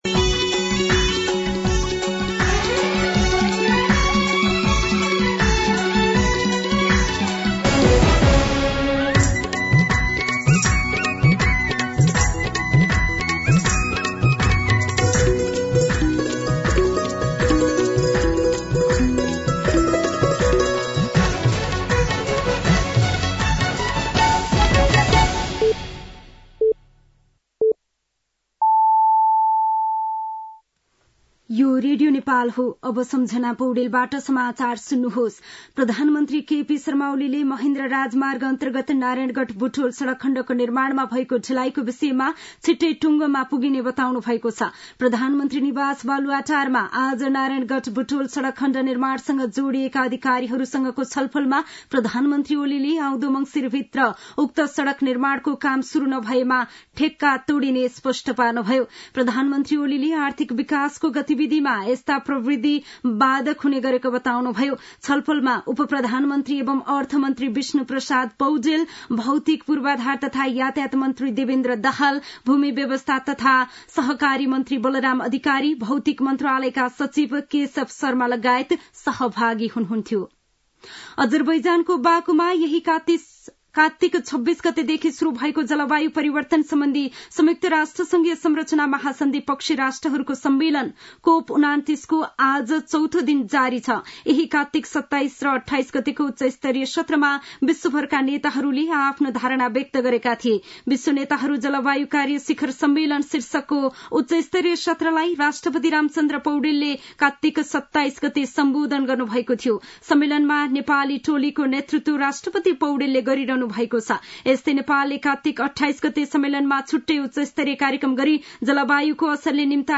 An online outlet of Nepal's national radio broadcaster
दिउँसो ४ बजेको नेपाली समाचार : ३० कार्तिक , २०८१
4-pm-nepali-news.mp3